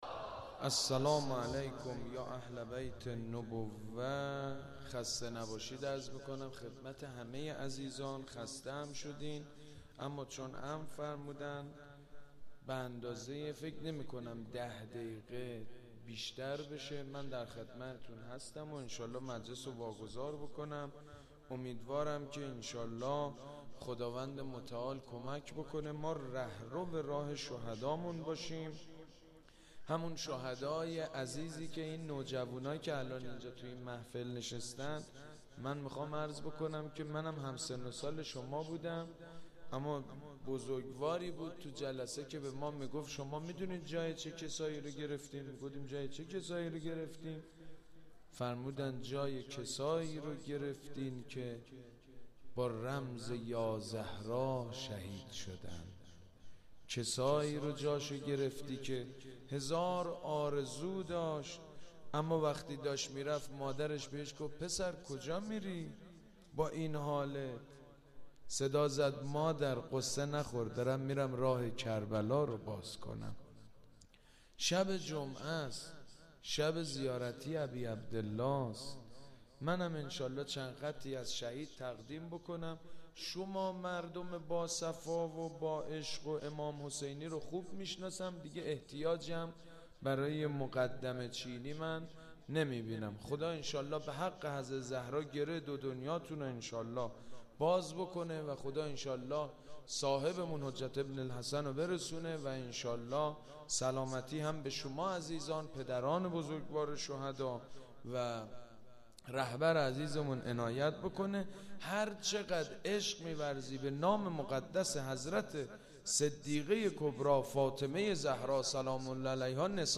مداحی حاج سید مجید بنی فاطمه در یادواره شهدای مسجد پنبه چی
در پایان مراسم نیز سید مجید بنی فاطمه نیز به مرثیه خوانی و مداحی در رثای شهدای کربلا و شهدای جنگ تحمیلی پرداخت.